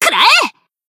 BA_V_Saki_Swimsuit_Battle_Shout_2.ogg